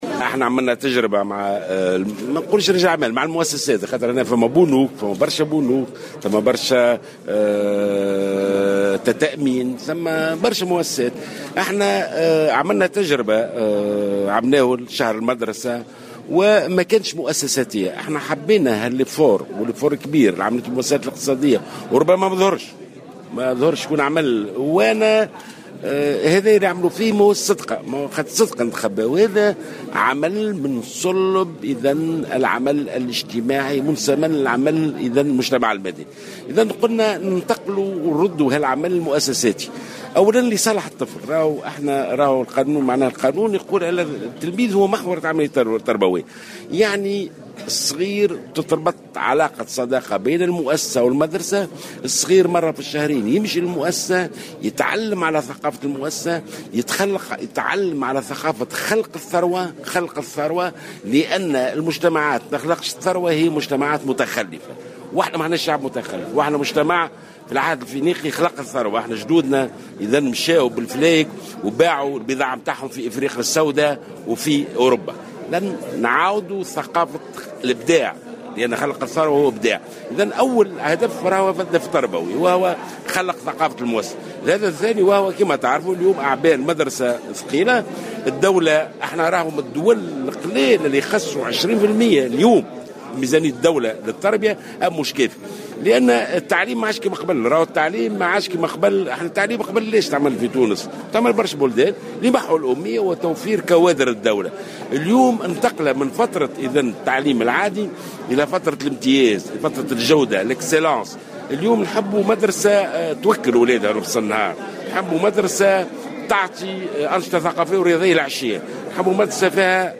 وبين الوزير في تصريح لمراسل الجوهرة أف أم، أن هذه الخطة ستمتد على أجل أقصاه 3 أشهر بالتوازي مع شهر المدرسة، بهدف خلق جيل من الشباب الذي يؤمن بثقافة المؤسسة والإبداع وخلق الثروة، منوّها بالإقبال الجيد لرجال الأعمال على معاضدة مجهود الدولة لاصلاح المؤسسات التربوية.